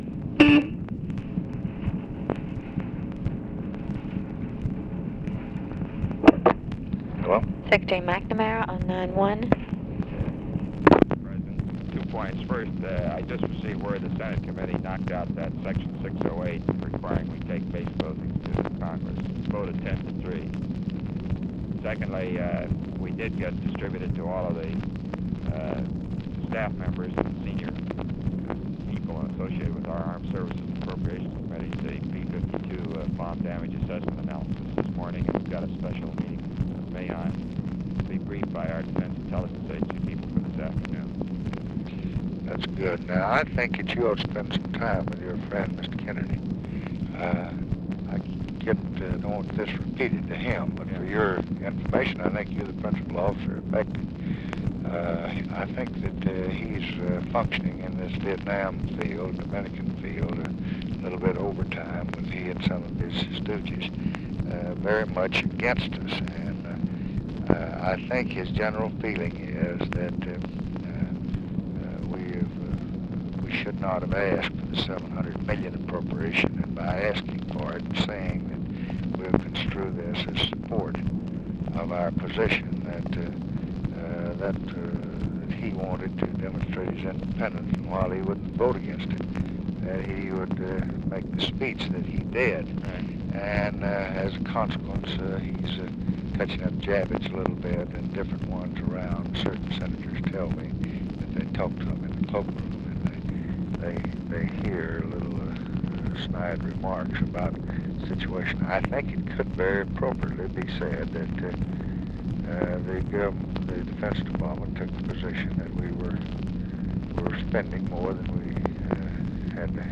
Conversation with ROBERT MCNAMARA, June 21, 1965
Secret White House Tapes